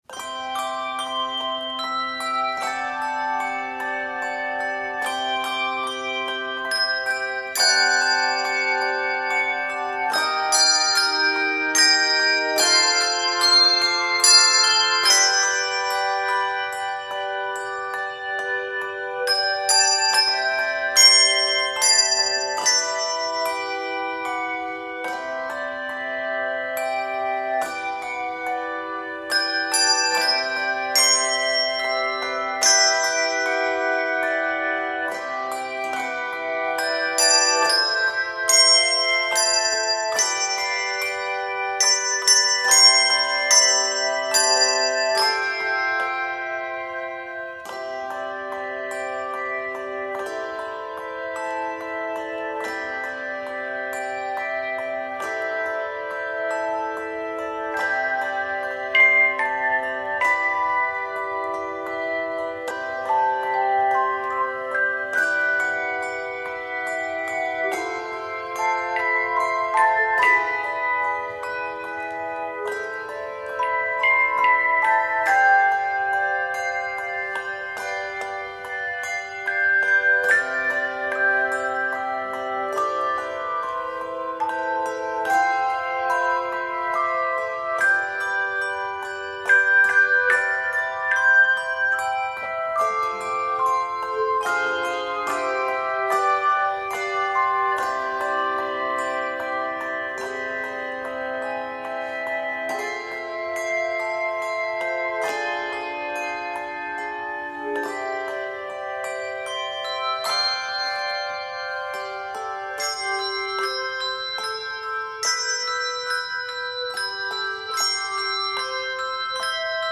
creates a sense of floating on a gentle breeze